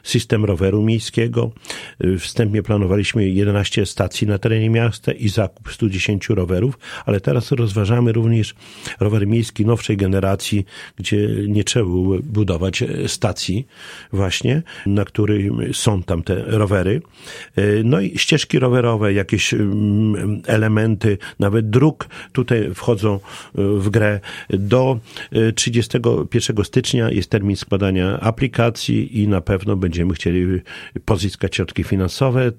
Szczegóły projektu przedstawił Czesław Renkiewicz, prezydent Suwałk.